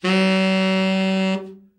Index of /90_sSampleCDs/Giga Samples Collection/Sax/TENOR OVERBL
TENOR OB   1.wav